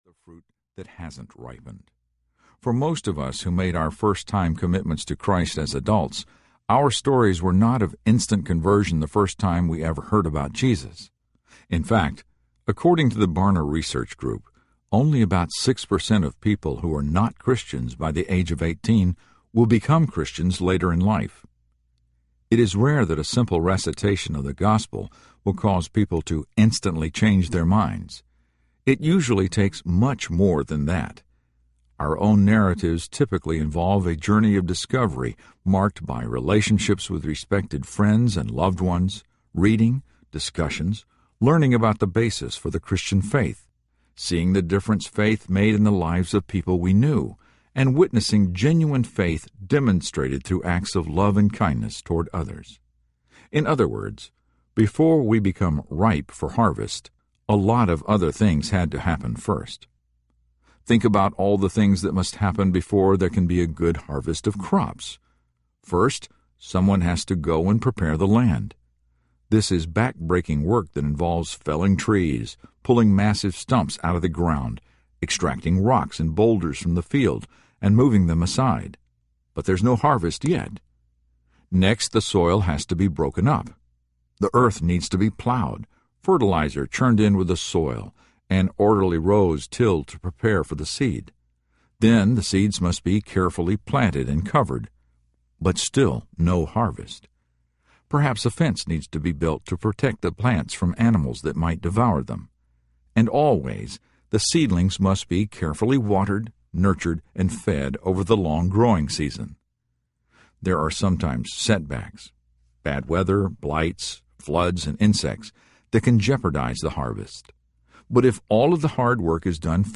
The Hole In Our Gospel (Special Edition) Audiobook